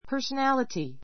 personality A2 pəː r sənǽləti パ～ソ ナ りティ 名詞 複 personalities pəː r sənǽlətiz パ～ソ ナ りティ ズ ❶ 個性, 性格; （人から好かれる） 人柄 ひとがら Her personality, not her beauty, made her popular.